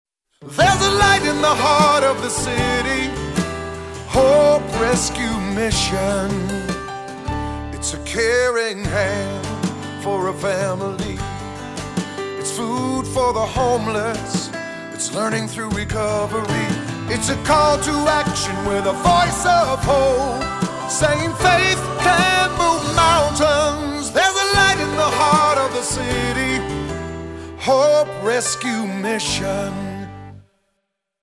Tags: Jingle Music Marketing Musical Image Branding